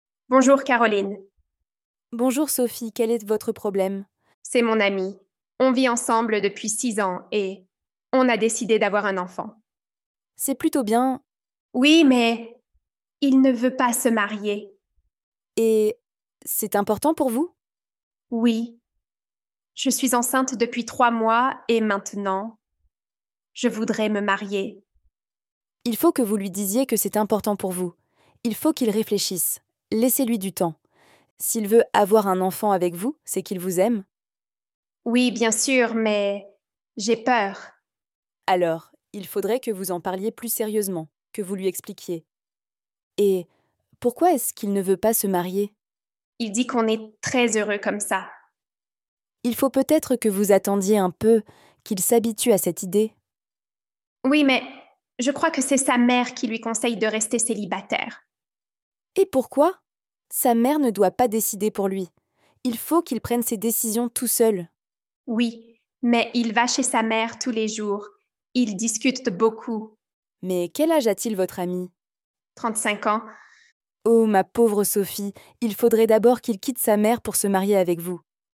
Dialogue – Il ne veut pas se marier